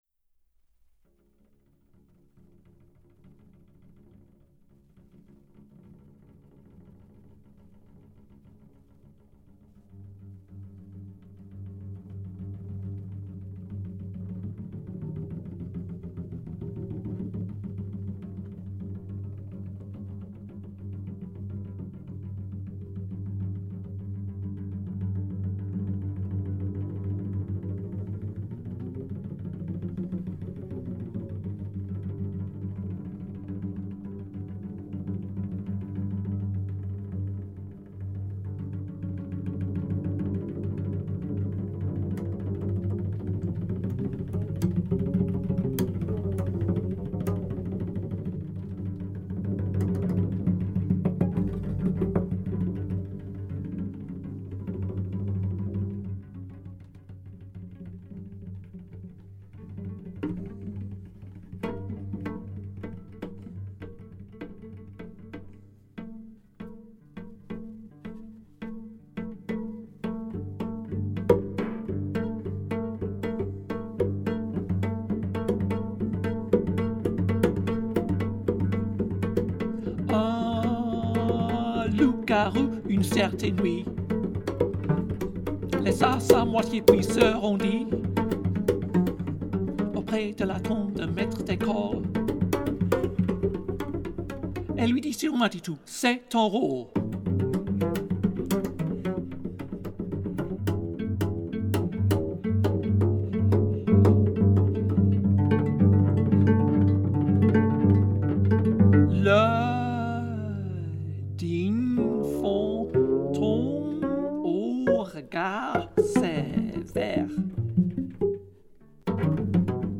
The use of extended techniques, originally intended for wide-scale deployment in the principal work, is essayed especially in two works for cello and speaking voice.